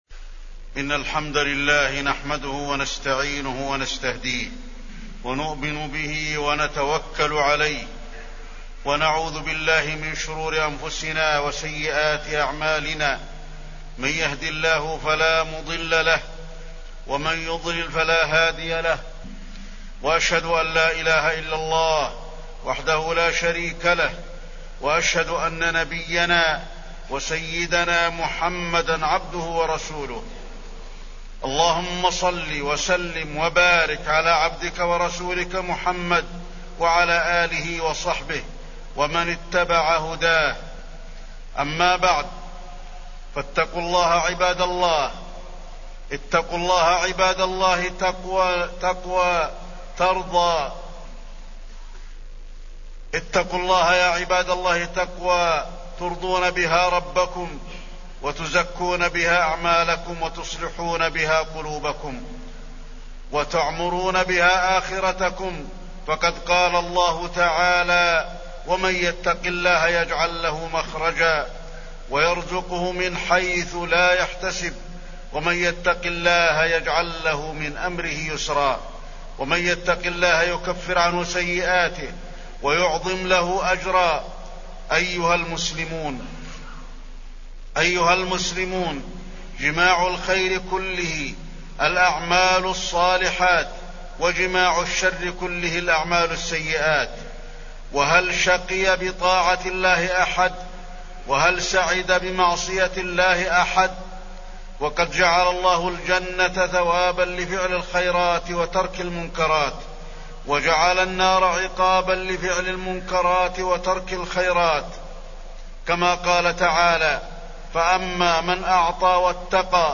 تاريخ النشر ٢٦ ربيع الثاني ١٤٢٩ هـ المكان: المسجد النبوي الشيخ: فضيلة الشيخ د. علي بن عبدالرحمن الحذيفي فضيلة الشيخ د. علي بن عبدالرحمن الحذيفي الأعمال الصالحة